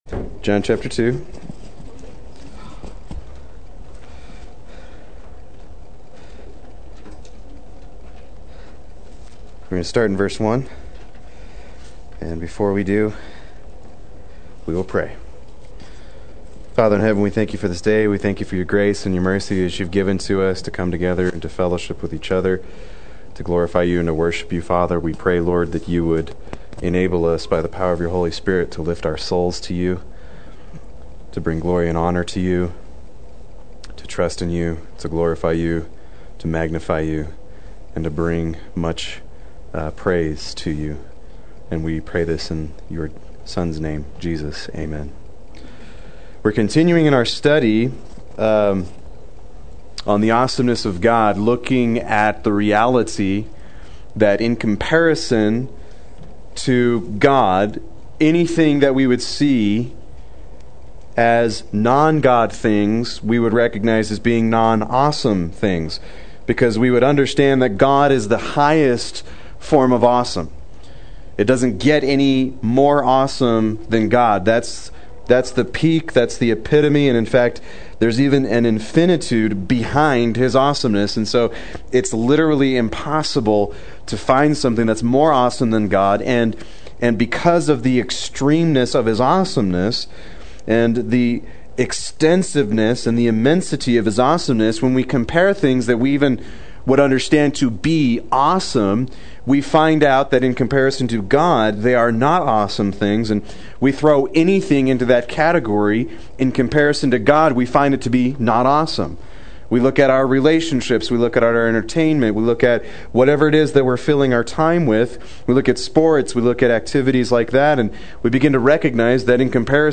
Proclaim Youth Ministry - 08/14/15
Play Sermon Get HCF Teaching Automatically.